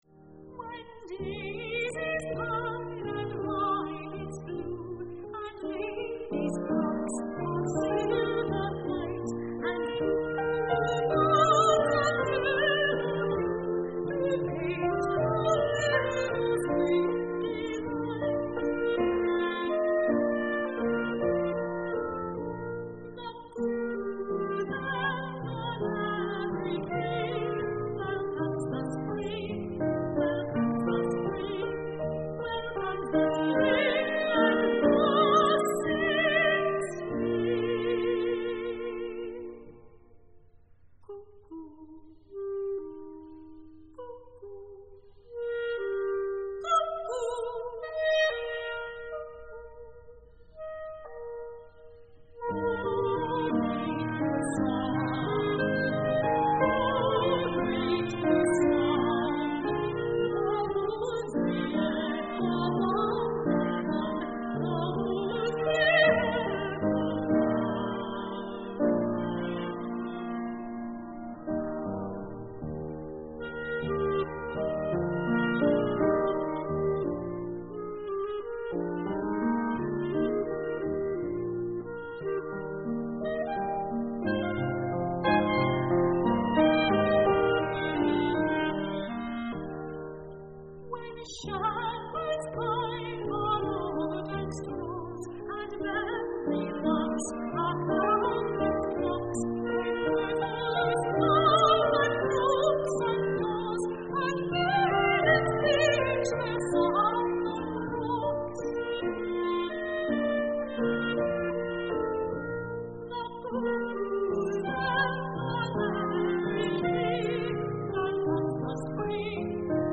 Soprano
Prelude to Spring Concert - Mechanics Hall, Worcester, MA USA
clarinet
piano